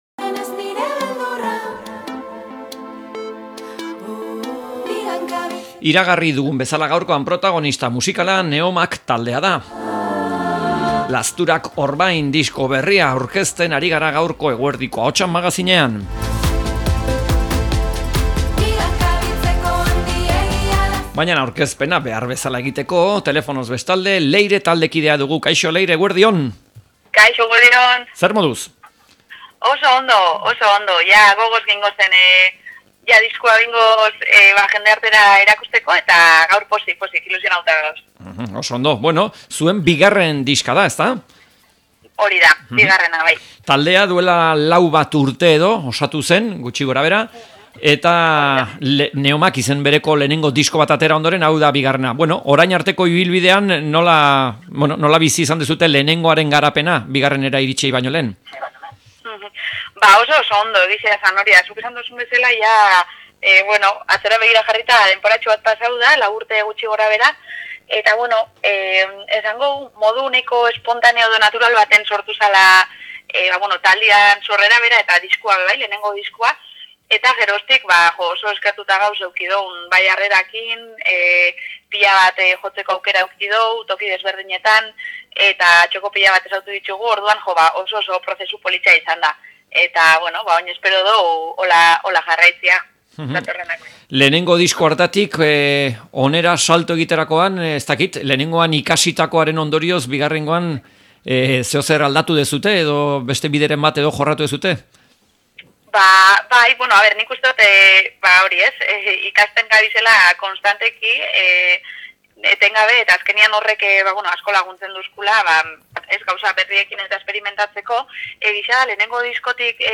Neomak taldeari elkarrizketa